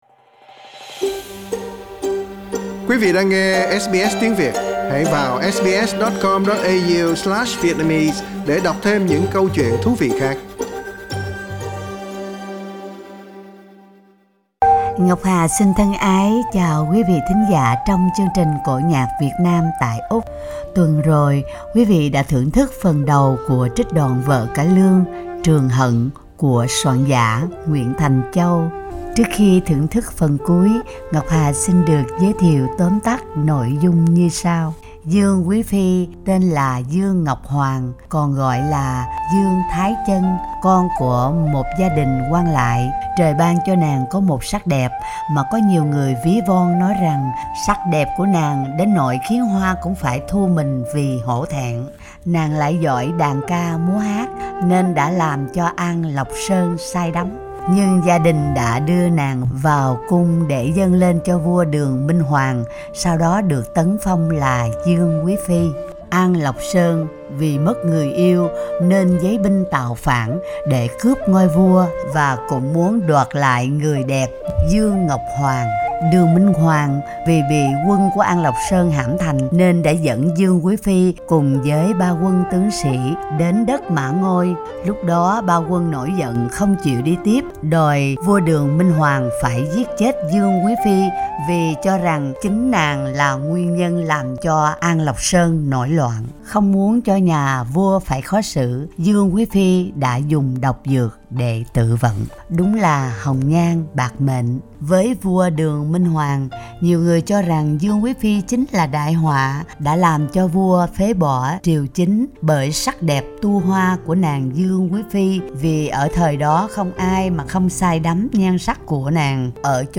cải lương